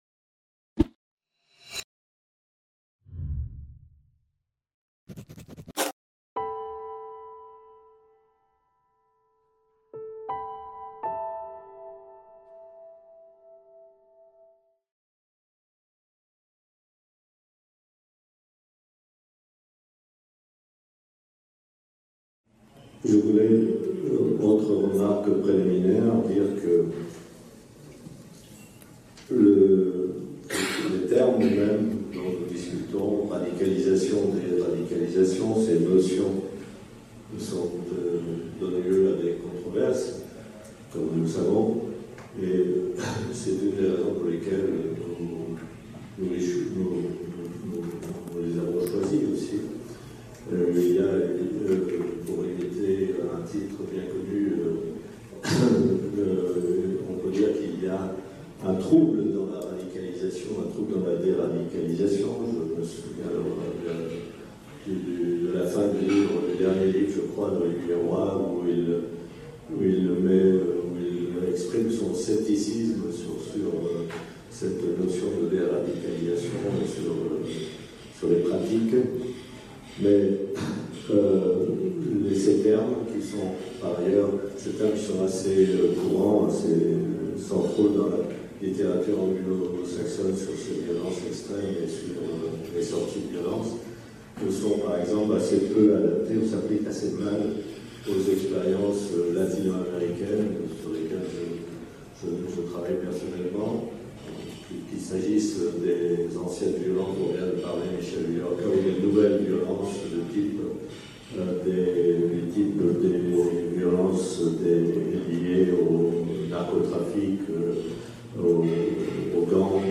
Dé-radicalisation, perspective comparatiste - IPEV - Colloque de Tunis | Canal U